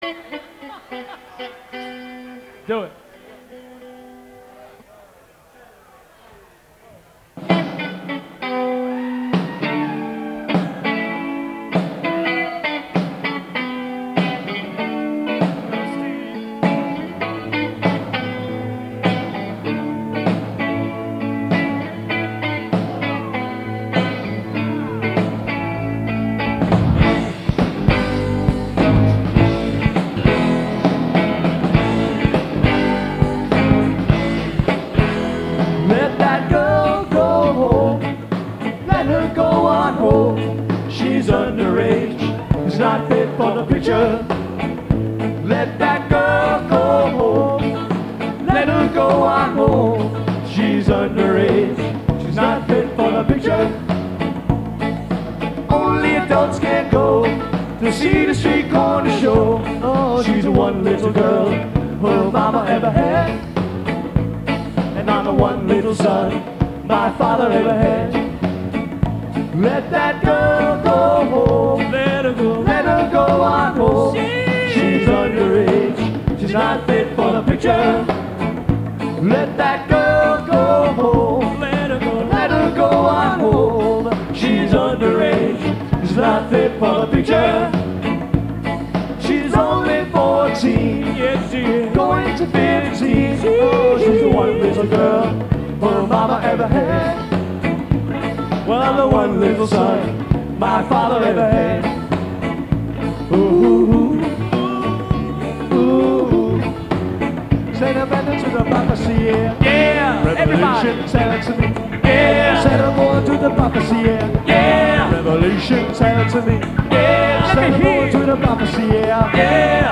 Live Recordings
August 17, 1996 - Shenanigan's Nite Club